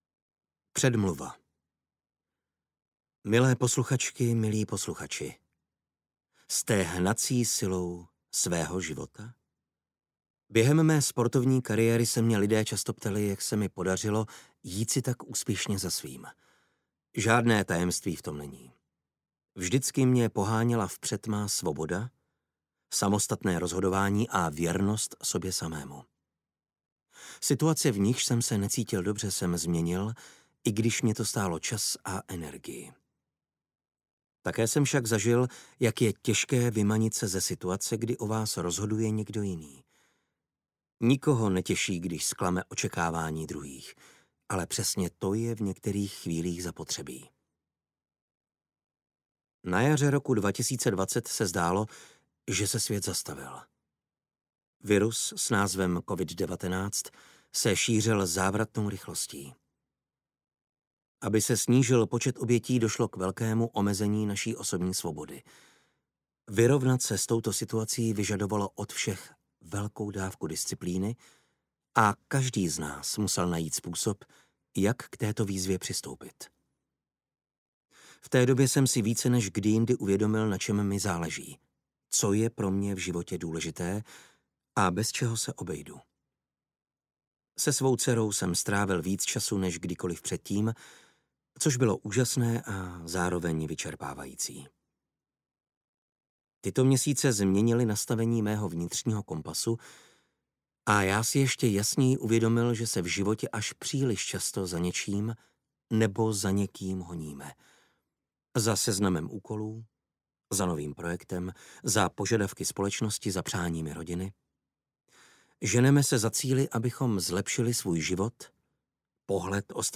Audioknihu můžete také darovat